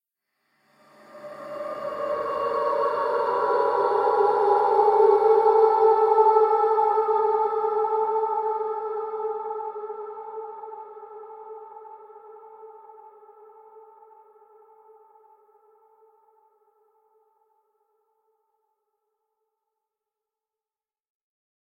Звуки призраков
Призрак тихонько поет жутко
prizrak_tihonko_poet_zhutko_rku.mp3